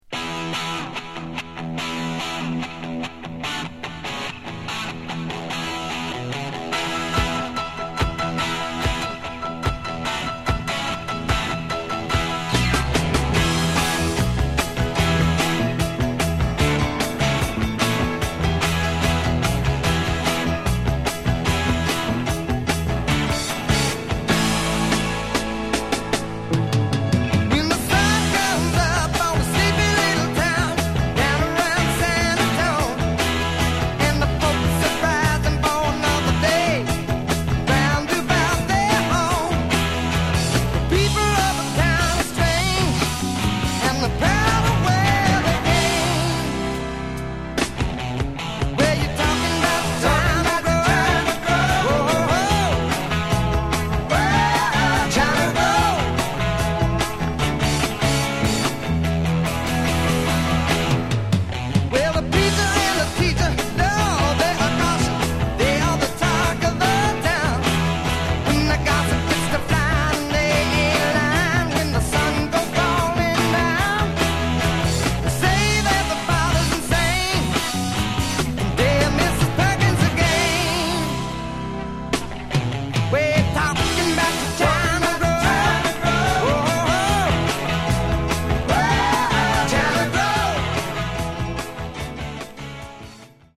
Genre: Country Rock